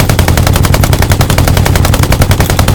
gun-turret-mid-1.ogg